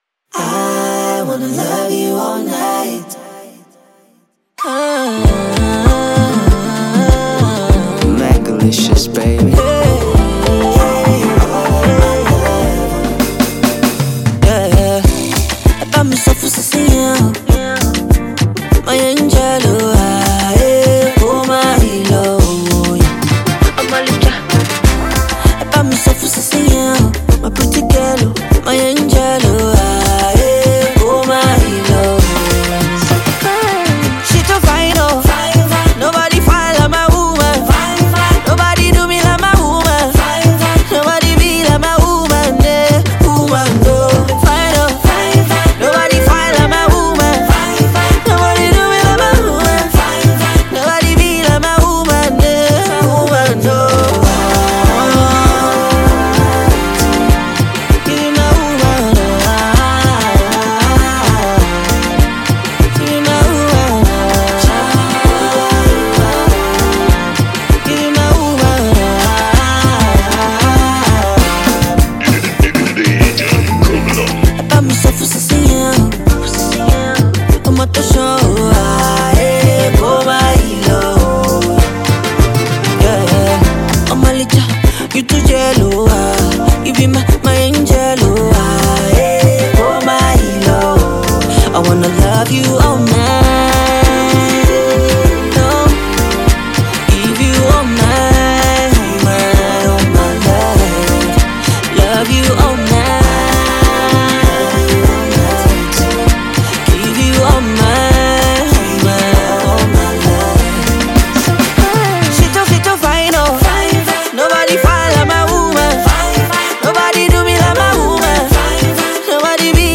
catchy Afrobeat number
is a lilting melody that serenades beauty
Afropop rendition